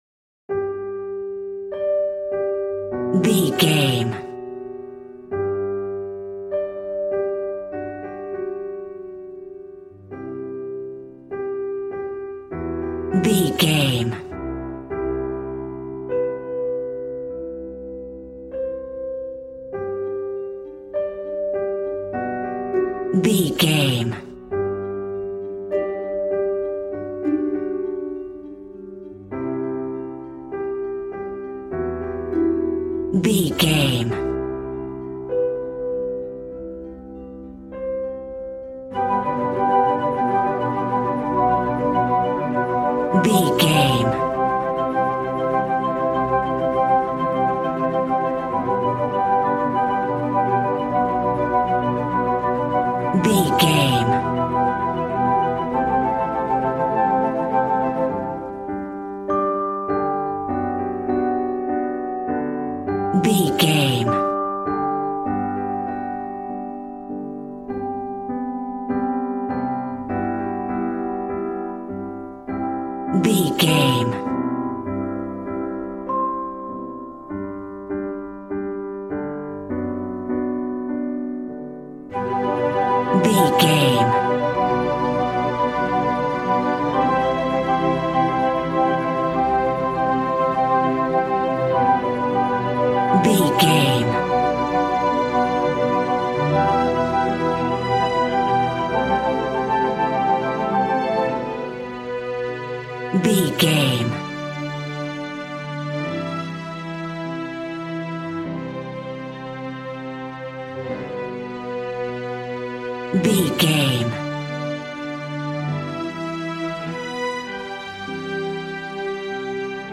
Ionian/Major
regal
strings
violin
brass